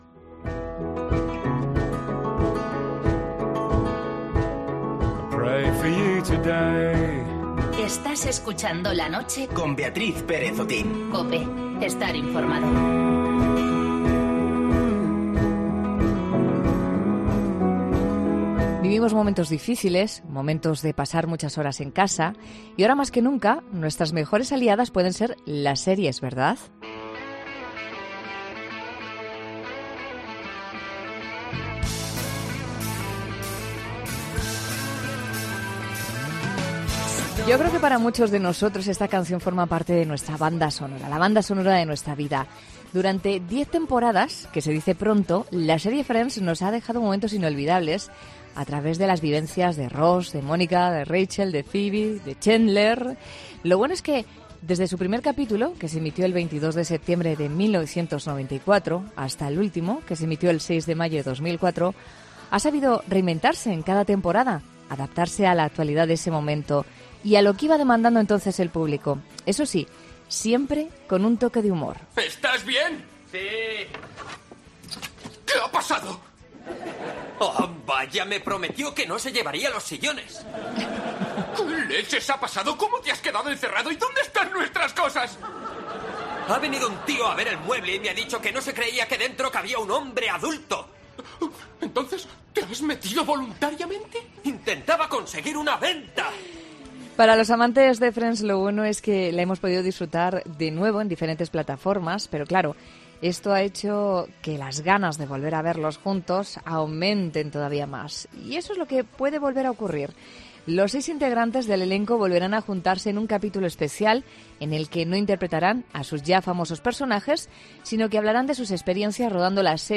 Parte del elenco de 'Friends' se vuelve a reunir en 'La Noche' de COPE